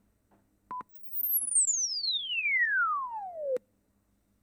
PallasPlus Chirp Test